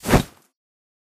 ui_interface_256.wav